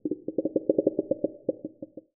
huskclicking1.ogg